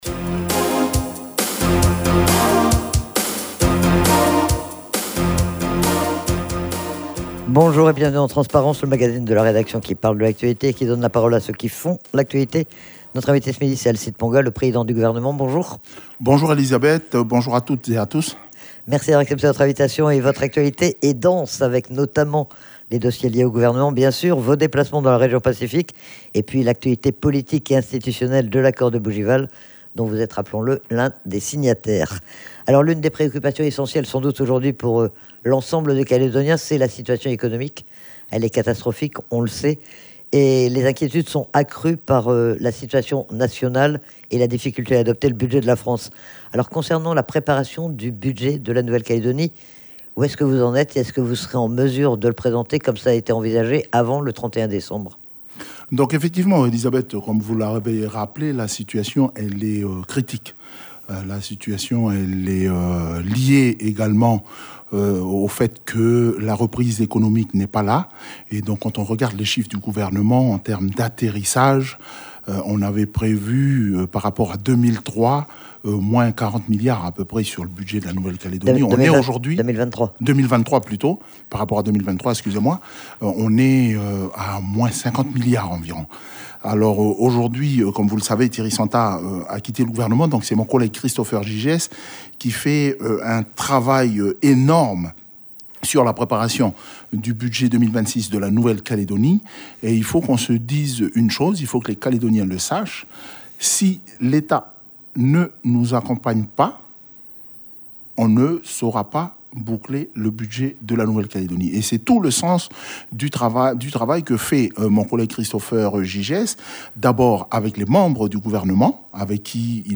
Il est interrogé sur ses déplacements dans la région Pacifique et notamment en Polynésie française mais aussi sur des sujets d'actualité comme le transfert d'Aircal à Tontouta, ou la préparation du budget de la Nouvelle-Calédonie. Alcide Ponga qui s'exprime aussi sur l'accord de Bougival dont il est l'un des signataires.